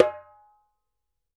ASHIKO 4 0SR.wav